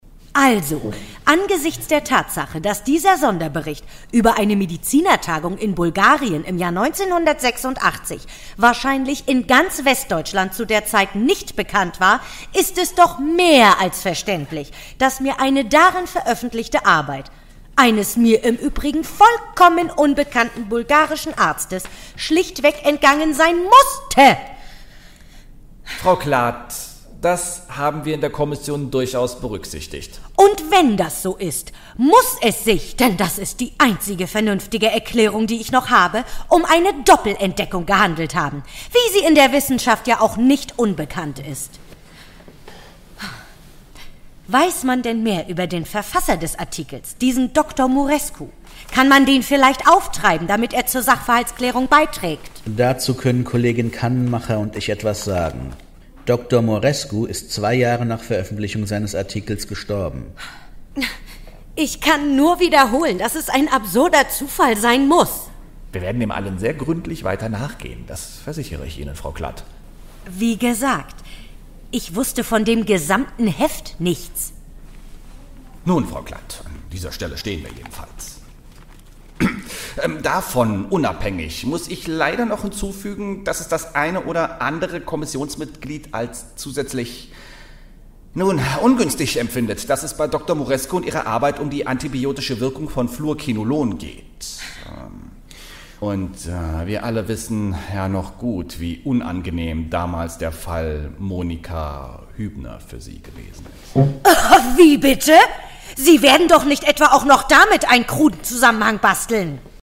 dunkel, sonor, souverän
Mittel plus (35-65)
Audio Drama (Hörspiel)